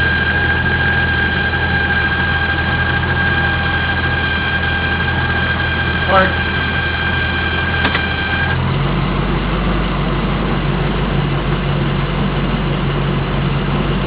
After driving the disco for about 20 minutes, a sound develops from the underside.
When placed in drive, the sound is audible.
Sorry to be such a nerd, but that sounds a lot like a hard drive bearing that's about to fail.